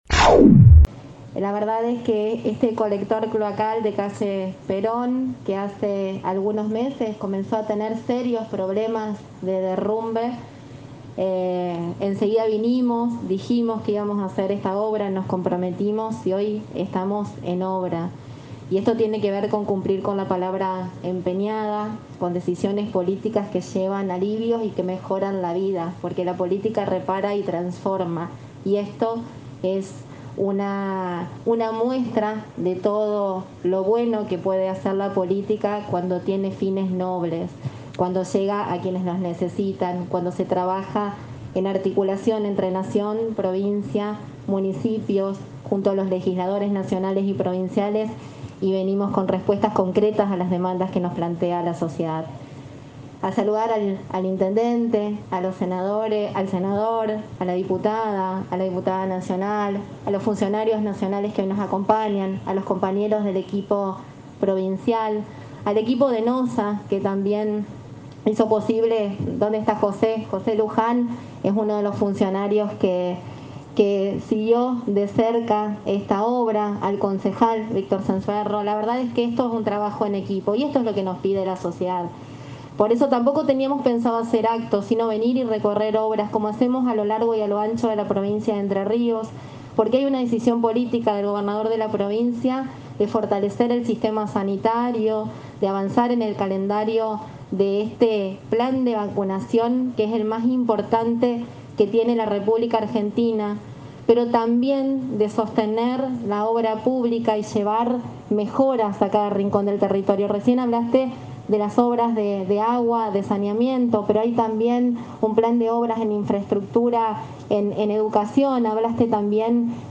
Luego de recorrer el club 25 de mayo donde se está llevando a cabo una nueva jornada de vacunación, la vicegobernadora asistió al acto de rehabilitación del Colector Perón.